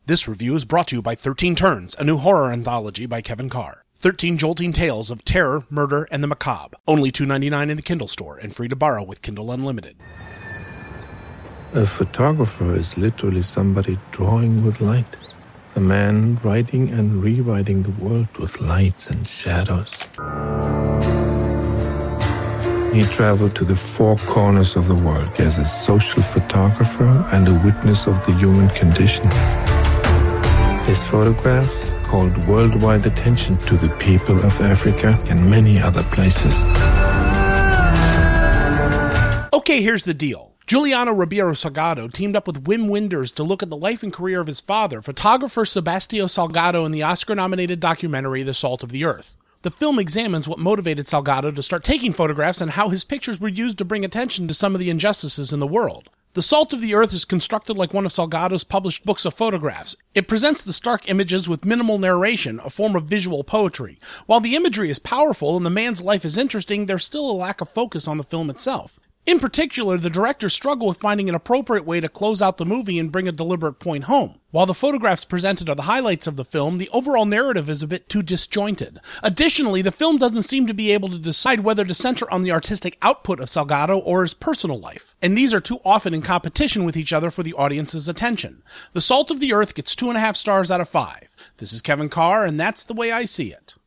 ‘The Salt of the Earth’ Movie Review